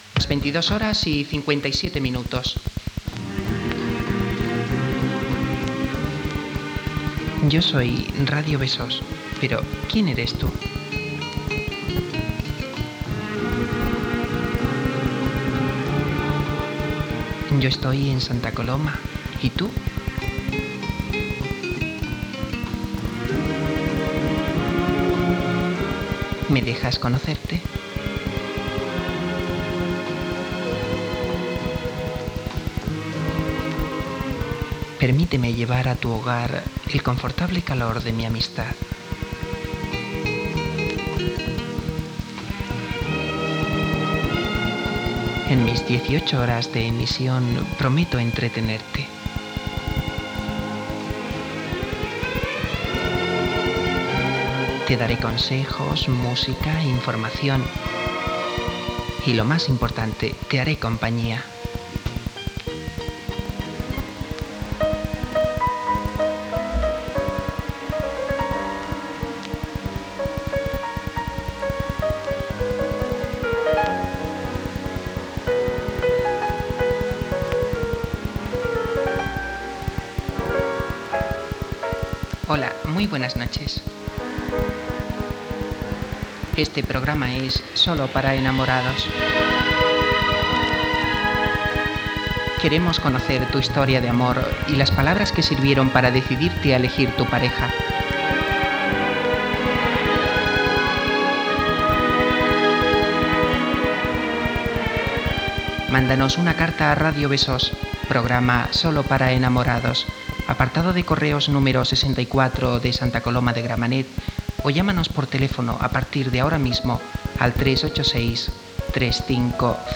Identificaciò i inici del programa